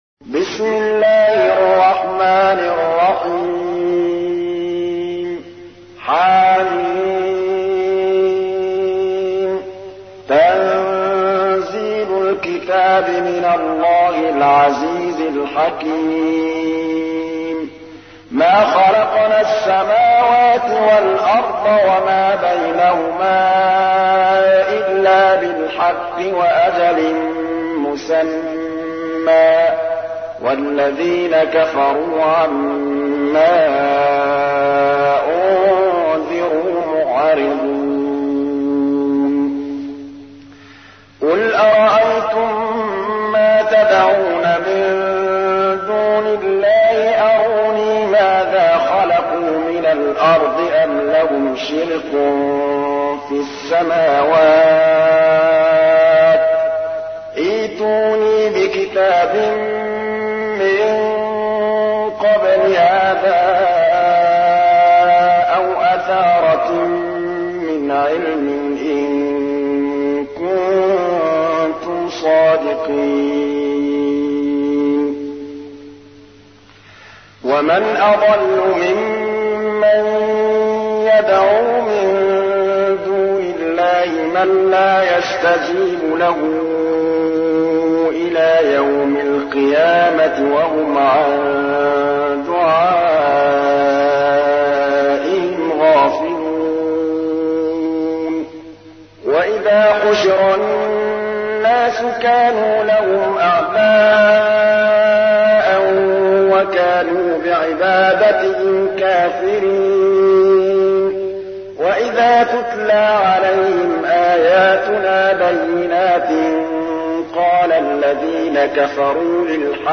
تحميل : 46. سورة الأحقاف / القارئ محمود الطبلاوي / القرآن الكريم / موقع يا حسين